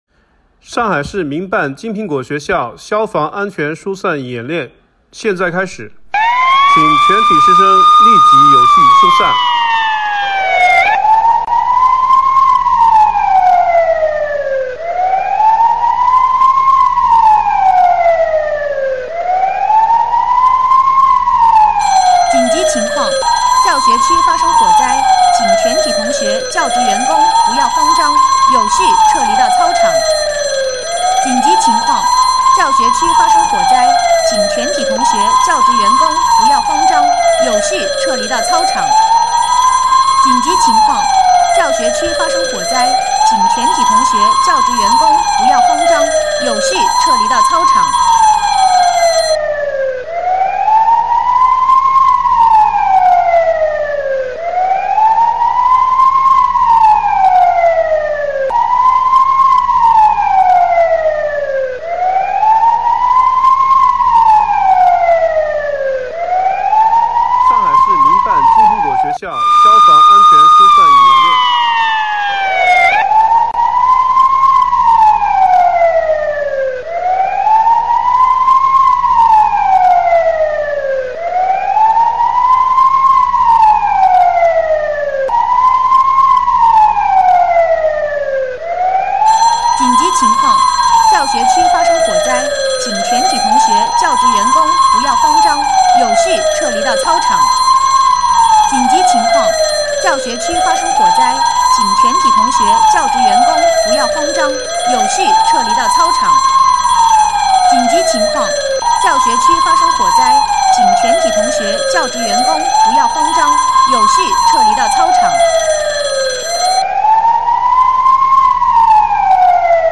上海市民办金苹果学校消防安全疏散演练警报mp3铃声